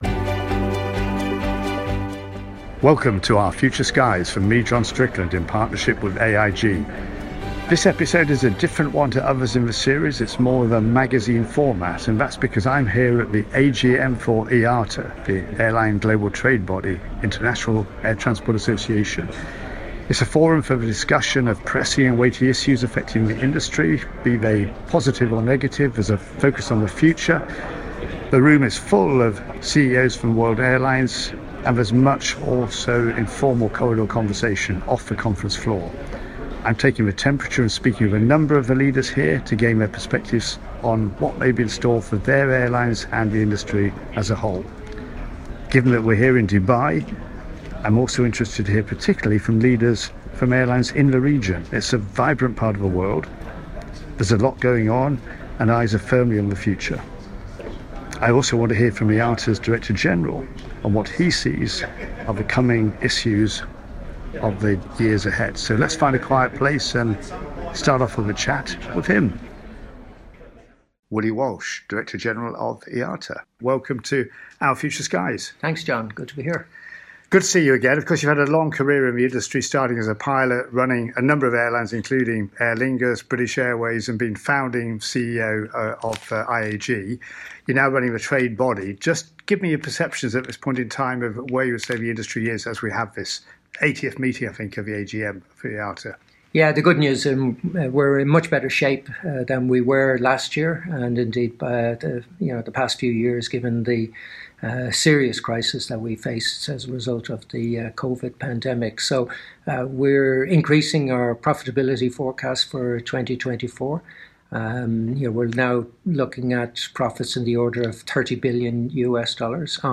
Where Next For Aerospace? - Conversations with industry leaders at the IATA AGM 2024